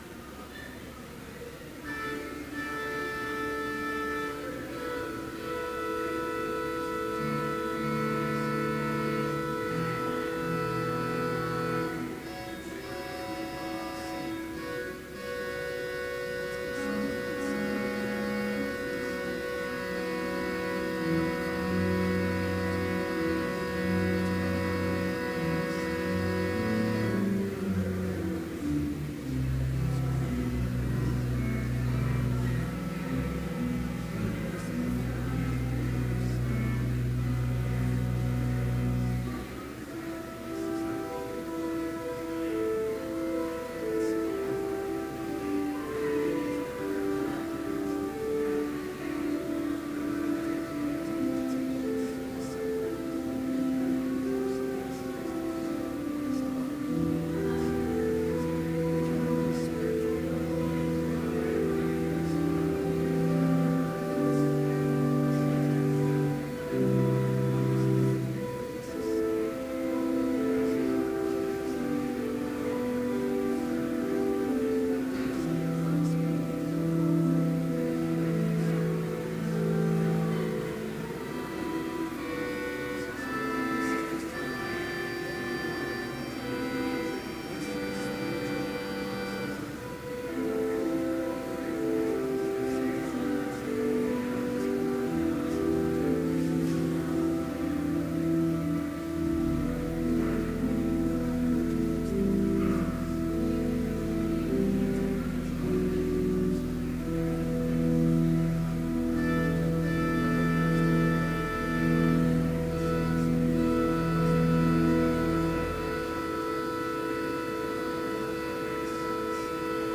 Complete service audio for Chapel - December 7, 2012